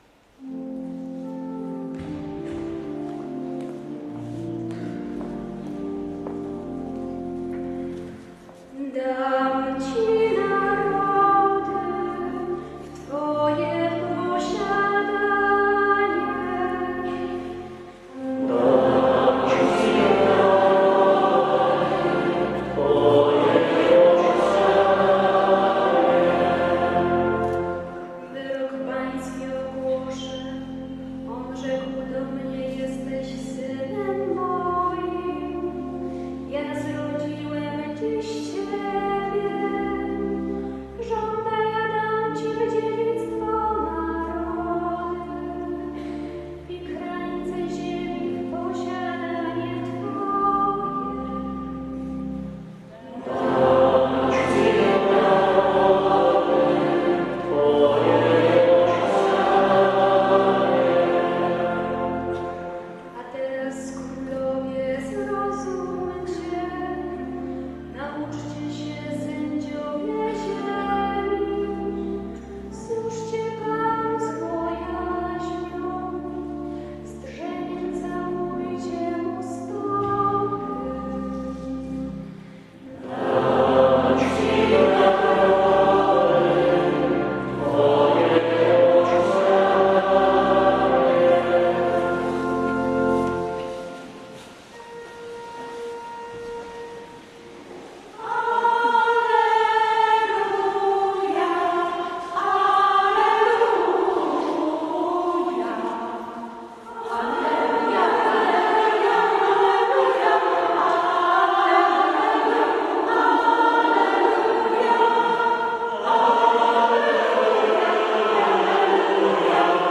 Godz. 10.00 – Msza Św.
oprawa muzyczna chórów parafialnych.